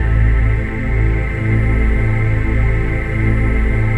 DM PAD2-02.wav